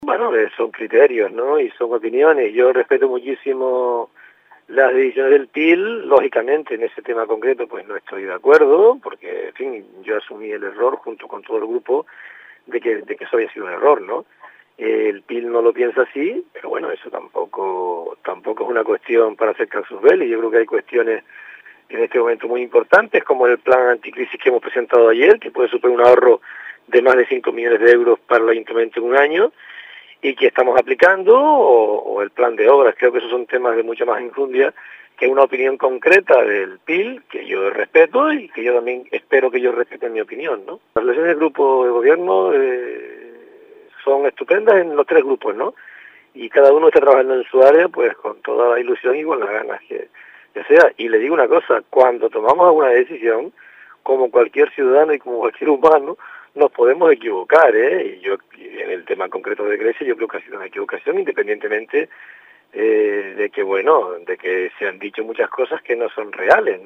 Escuche a Cándido Reguera, sobre las criticas que le hizo el PIL